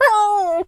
dog_hurt_whimper_howl_07.wav